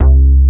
MKS80BSLC2-R.wav